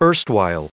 added pronounciation and merriam webster audio
960_erstwhile.ogg